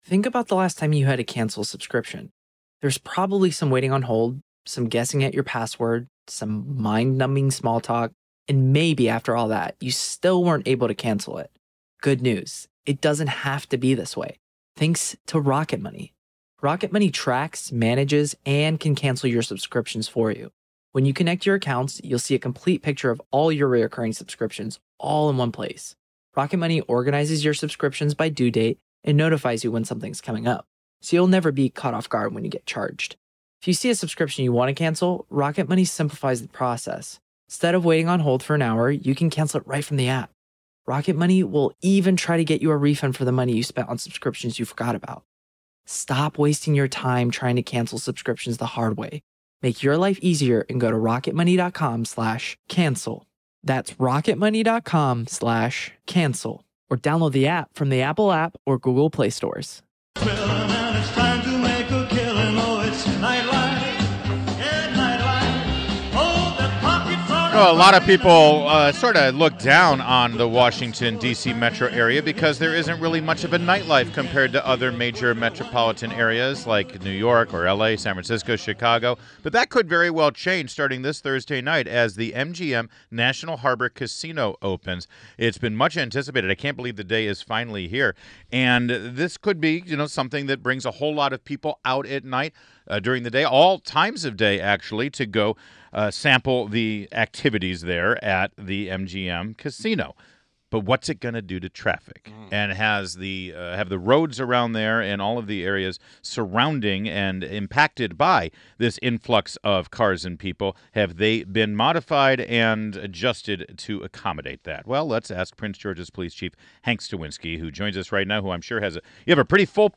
INTERVIEW — Prince George’s Police Chief HANK STAWINSKI